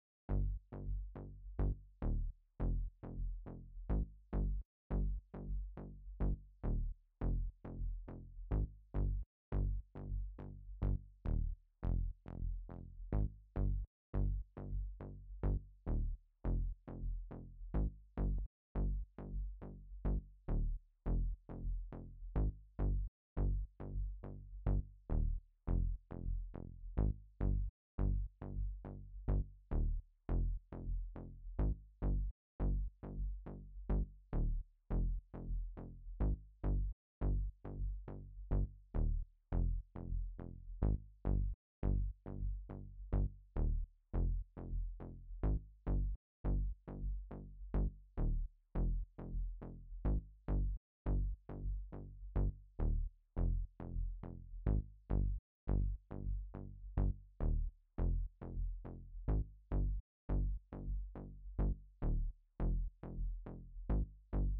Дело в том, что данный плагин при всех выключенных эффектах сам меняет звучание. Особенно это заметно при проигрывании одной небольшой партии в течении долгого времени.